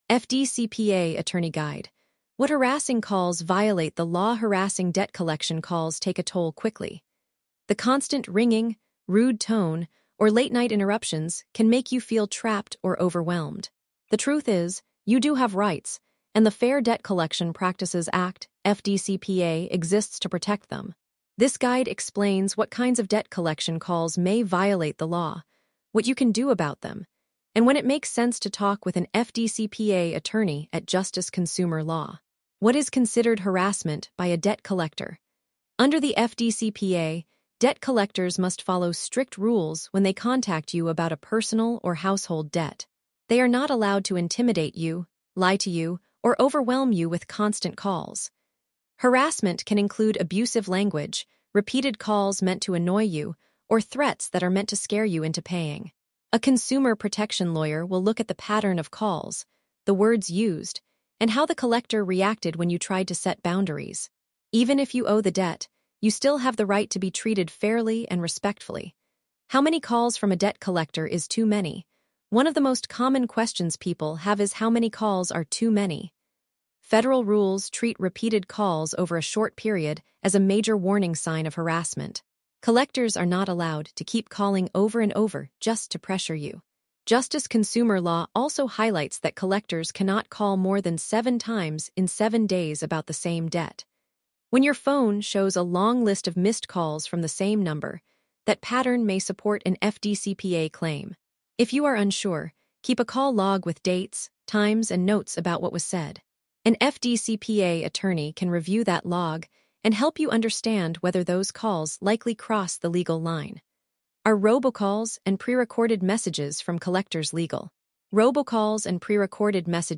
Voice version of the article on the consumer protection Attorney Guide: What Harassing Calls Violate the Law